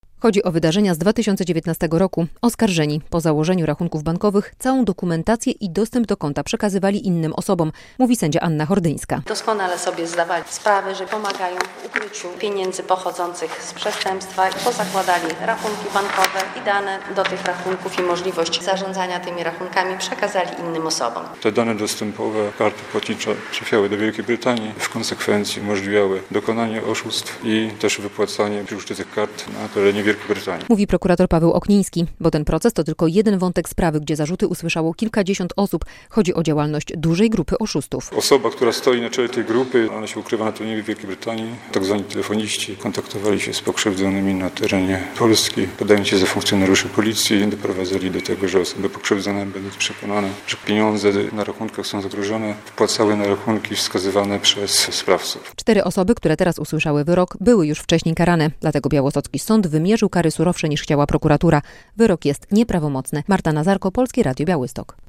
Wyrok na "słupy" - relacja